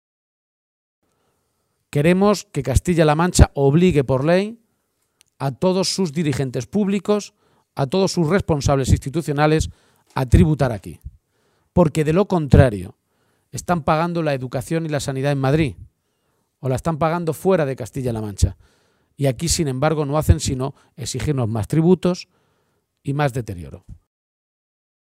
La dirección regional del Partido Socialista de Castilla-La Mancha se ha reunido esta tarde en Talavera de la Reina. Antes del inicio de esa reunión, el secretario regional, Emiliano García-Page, ha atendido a los medios de comunicación y ha anunciado que esta tarde, la Ejecutiva regional aprobará una resolución que se convertirá esta semana en iniciativa parlamentaria “para que todos los altos cargos públicos regionales paguen sus impuestos en Castilla-La Mancha”.